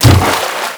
fs_water_colo2.wav